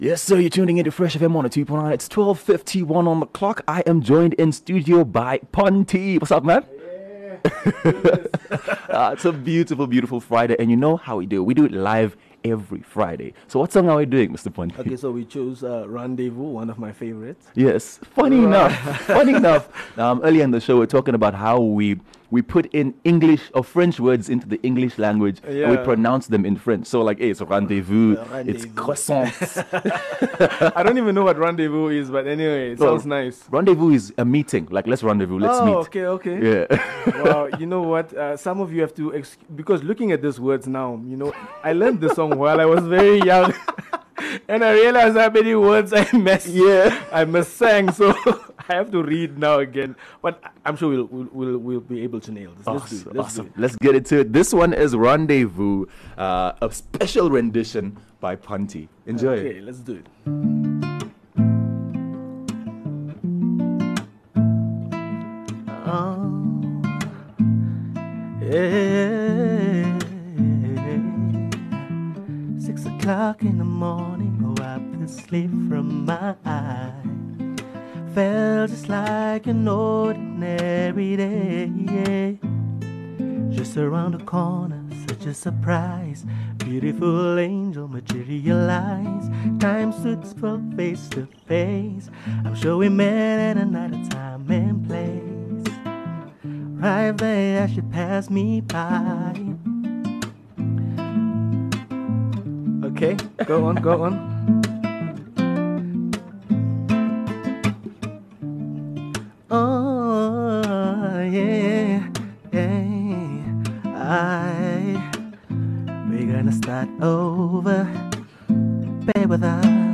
Live jams and groovey tunes with local artist